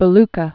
(bə-lkə, byĕ-lə)